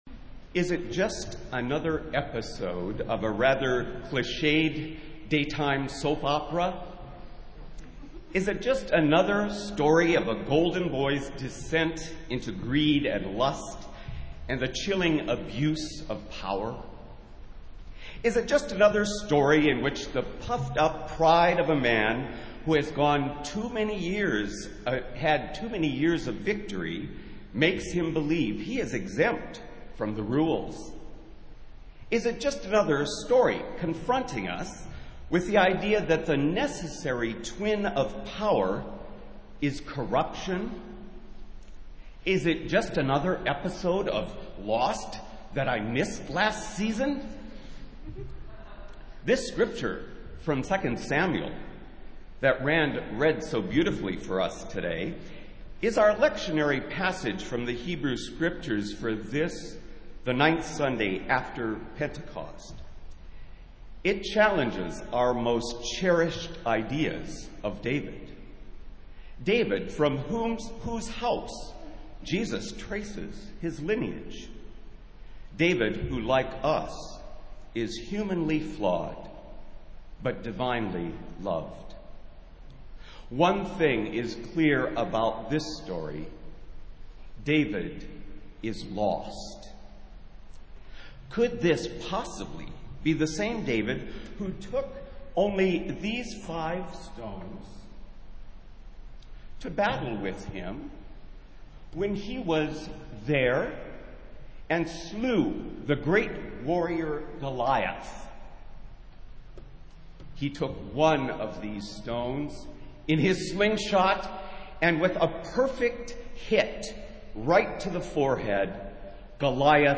Festival Worship - Ninth Sunday after Pentecost